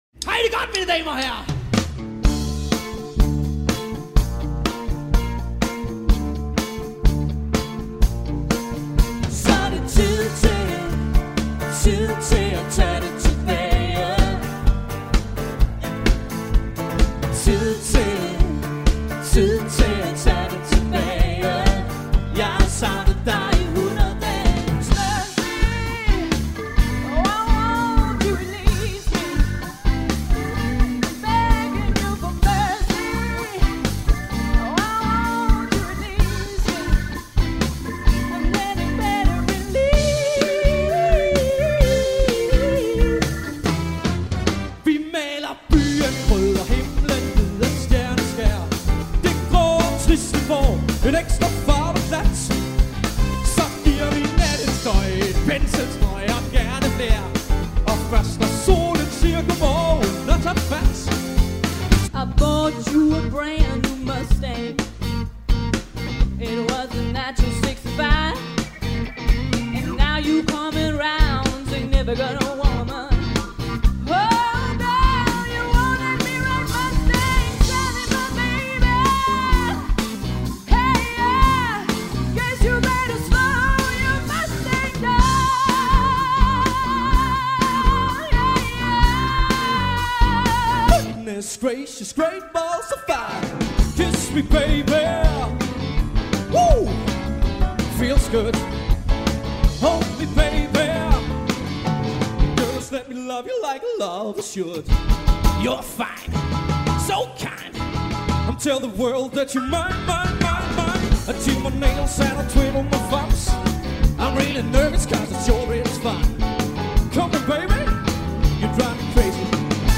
• Allround Partyband
• Coverband